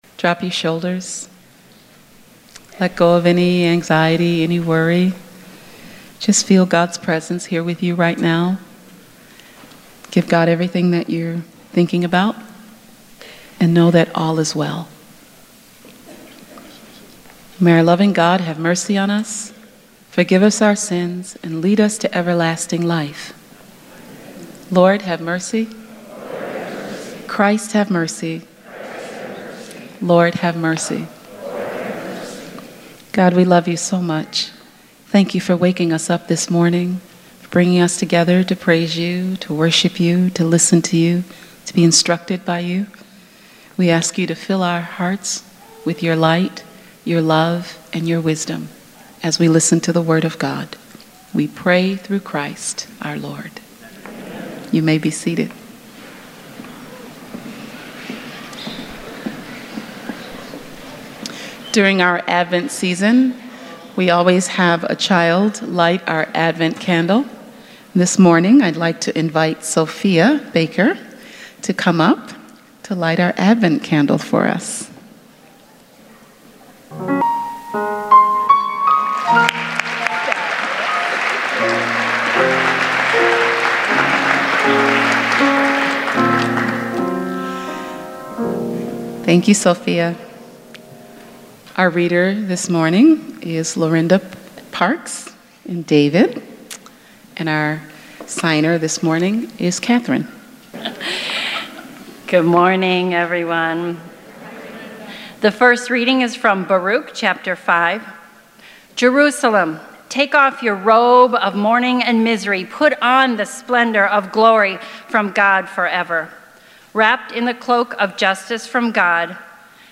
Spiritus Christi Mass December 9th, 2018